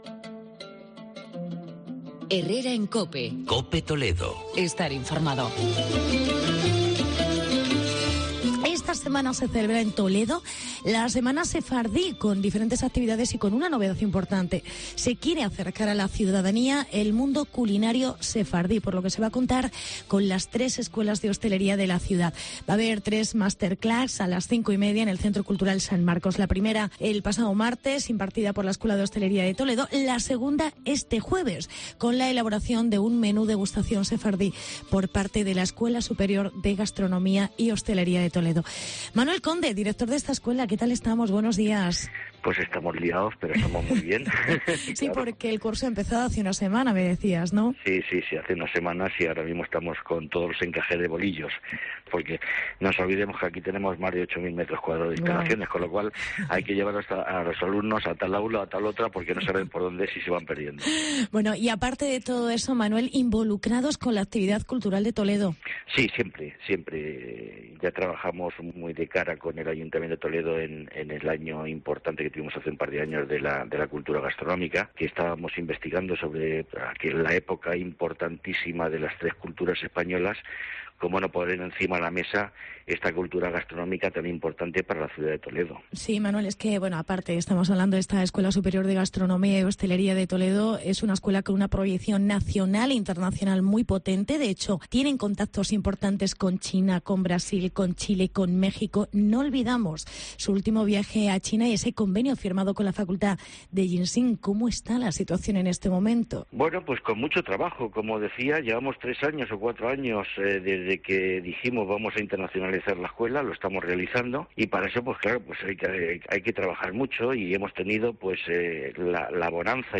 El viaje de Page y los hosteleros a China dará sus frutos en dos meses... Entrevista